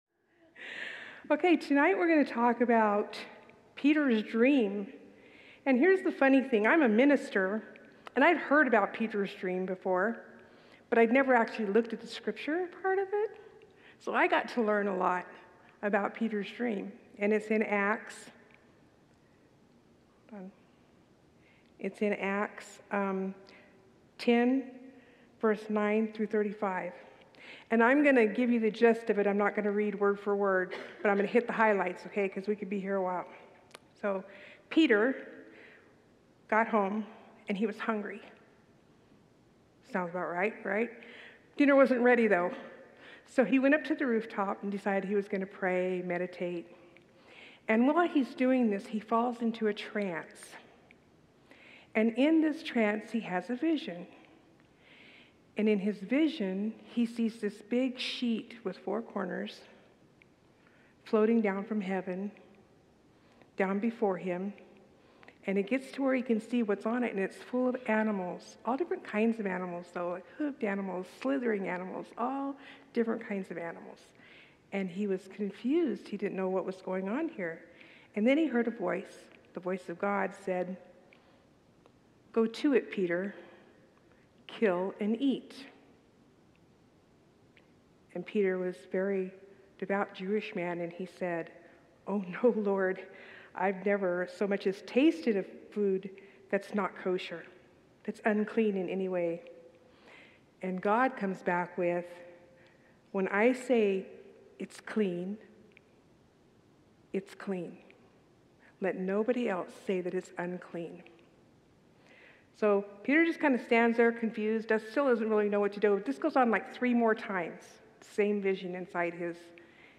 Series: Wednesday Evening Worship